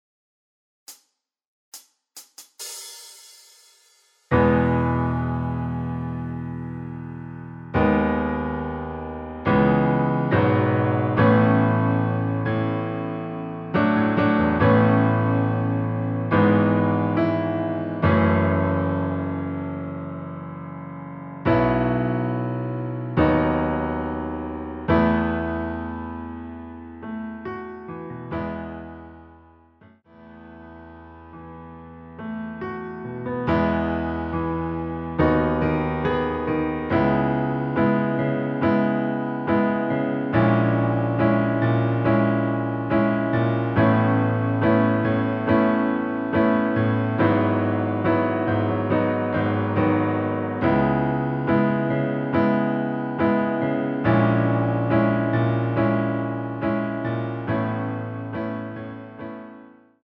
반주가 피아노 하나만으로 제작 되었습니다.(미리듣기 확인)
전주없이 노래가시작되는곡이라 카운트 만들어 놓았습니다.
원키(Piano Ver.) MR입니다.
앞부분30초, 뒷부분30초씩 편집해서 올려 드리고 있습니다.
중간에 음이 끈어지고 다시 나오는 이유는